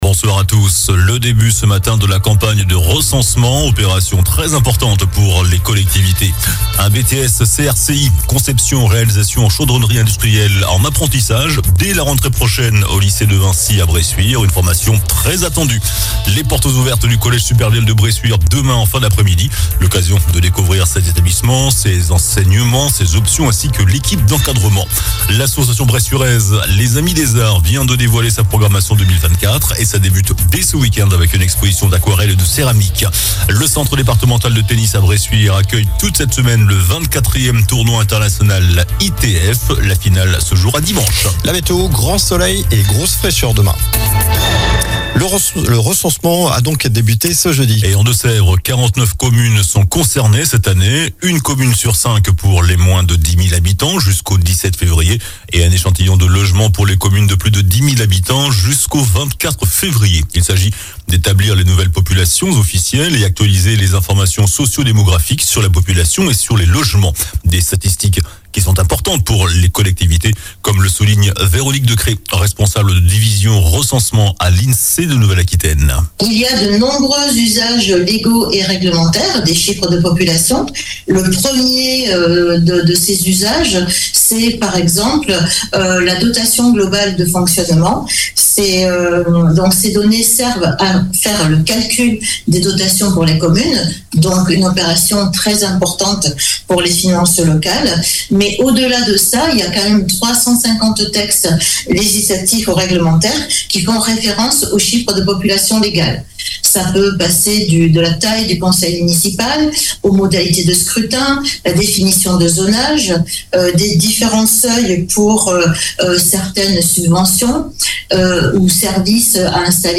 JOURNAL DU JEUDI 18 JANVIER ( SOIR )